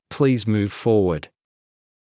ﾌﾟﾘｰｽﾞ ﾑｰﾌﾞ ﾌｫｰﾜｰﾄﾞ